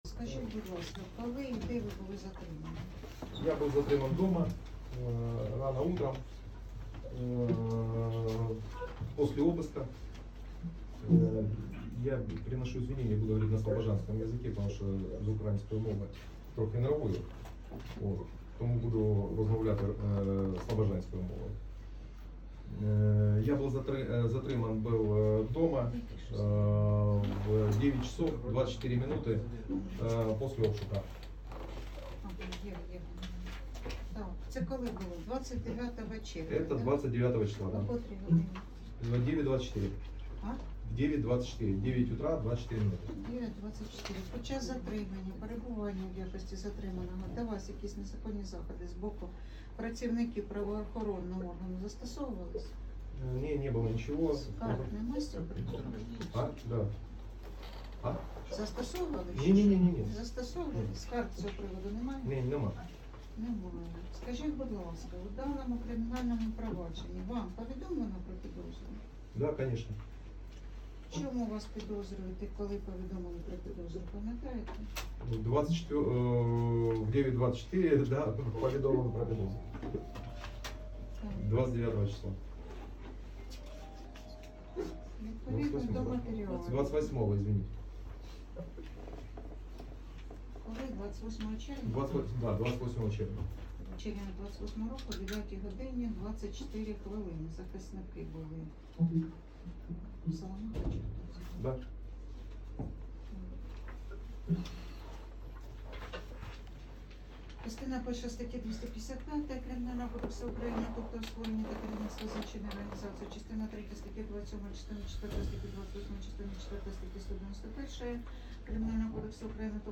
Руденка завели до судової зали, повідомляє кореспондент «МедіаПорта».